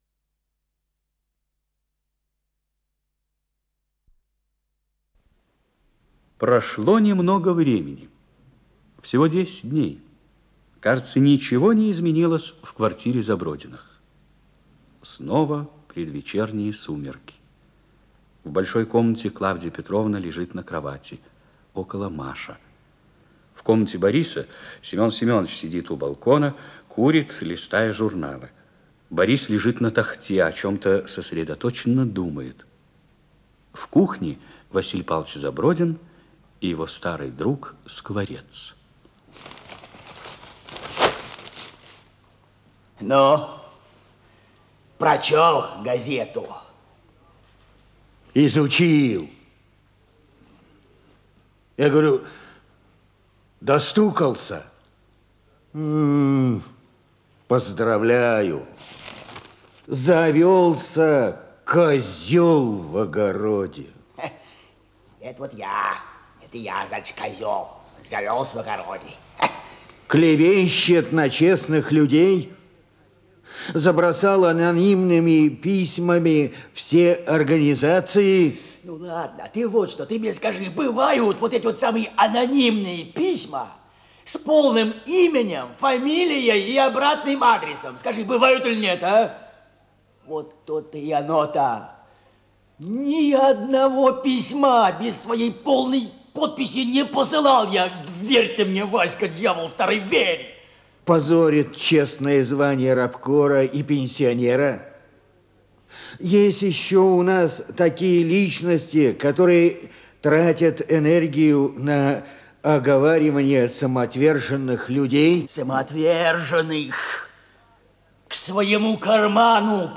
Название передачи Ленинградский проспект Подзаголовок Спектакль театра им. Моссовета